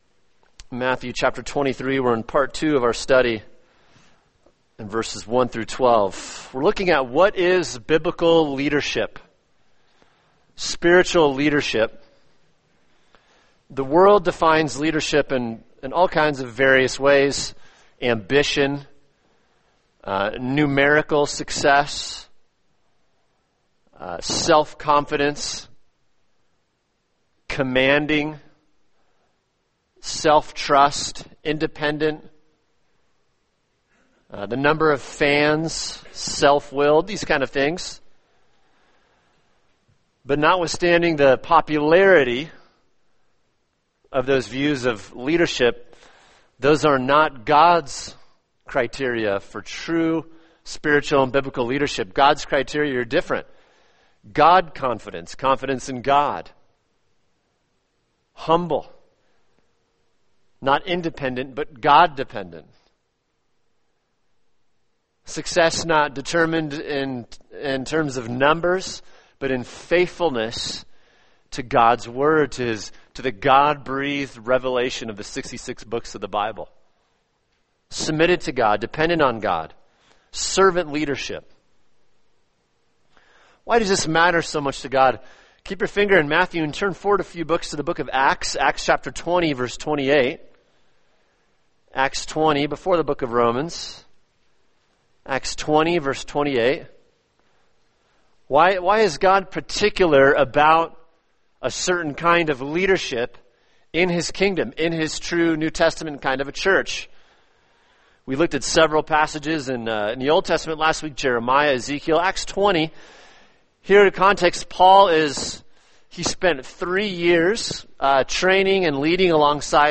[sermon] God’s Concern For Qualified Spiritual Leadership – Part 2 | Cornerstone Church - Jackson Hole